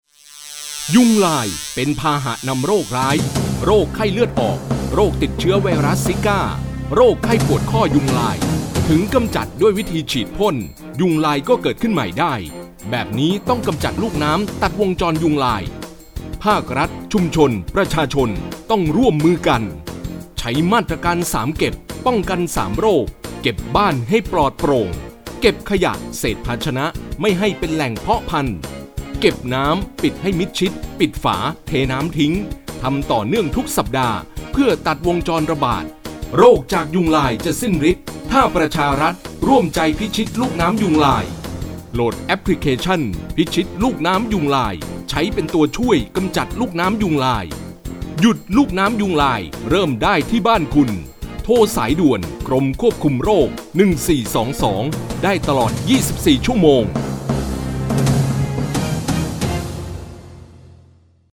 เชิญ Download !! สปอตวิทยุ ประชารัฐร่วมใจ ใช้ 3 เก็บป้องกัน 3 โรค